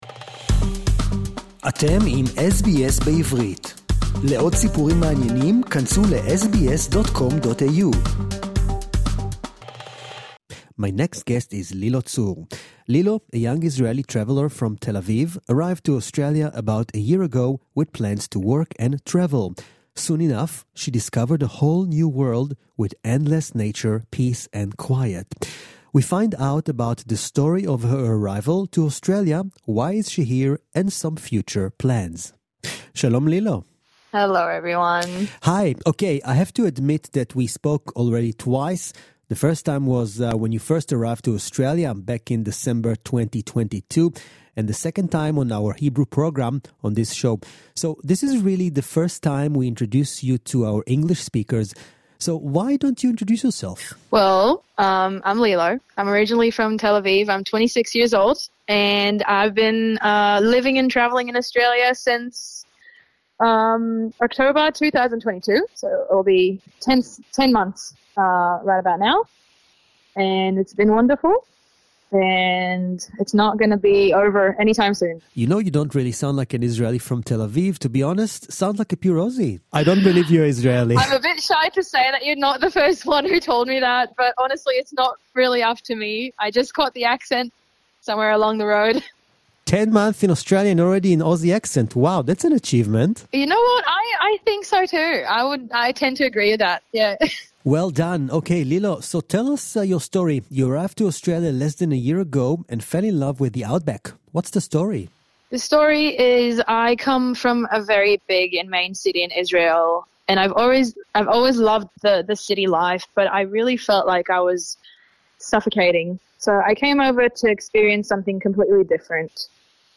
(English Interview) | SBS Hebrew